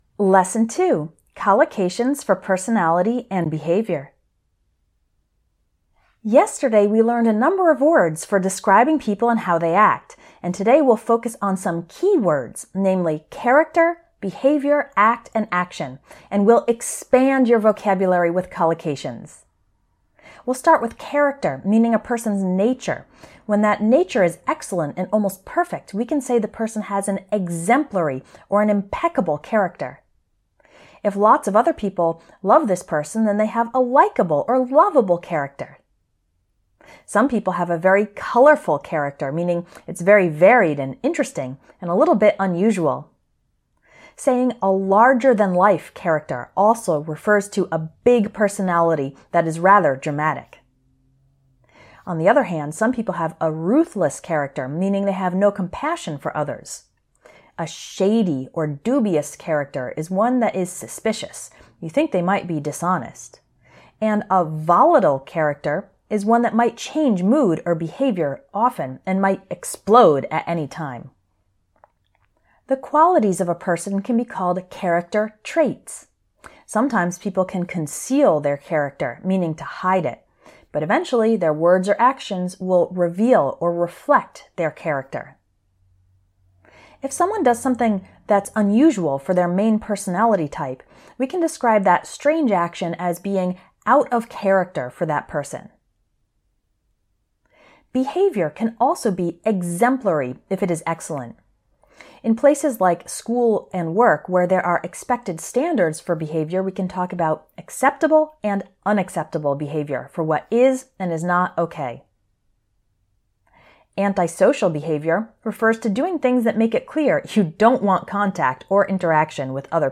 Lesson 02 - Collocations - Personality and Behavior.mp3